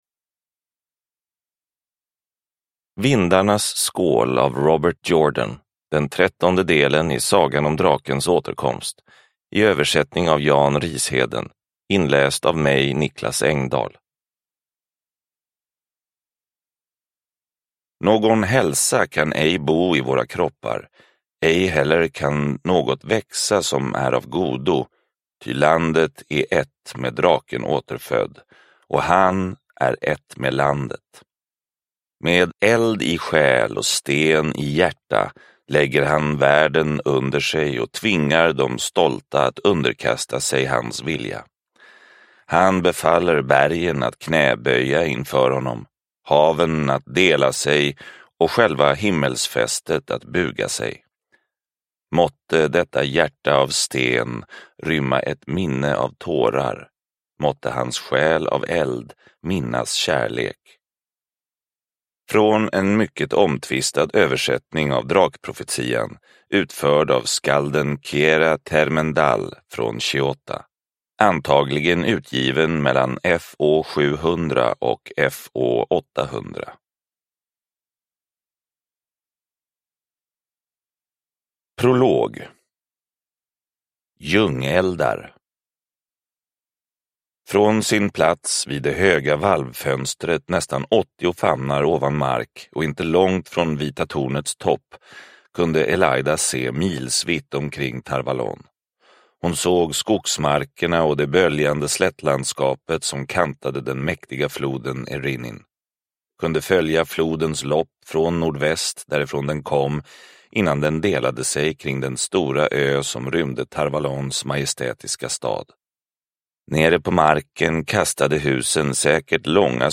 Vindarnas skål – Ljudbok – Laddas ner